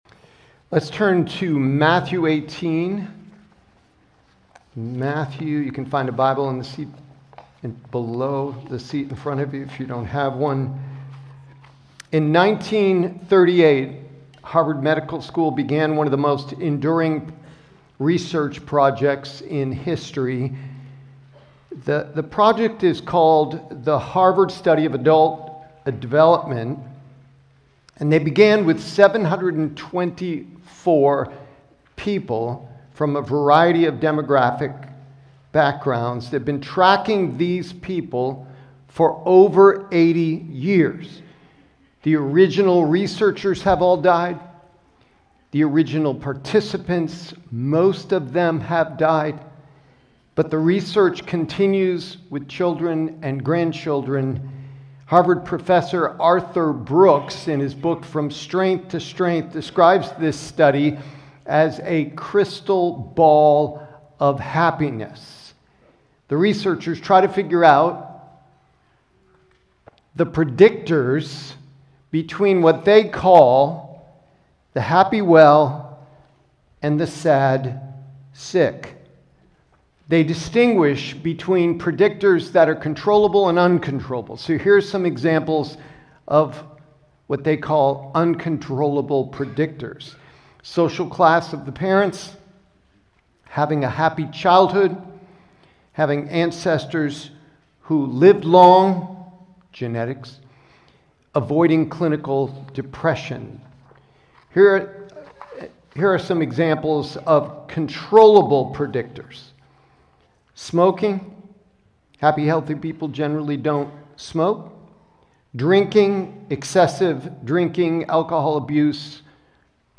Prev Previous Sermon Next Sermon Next Title The Value of Each One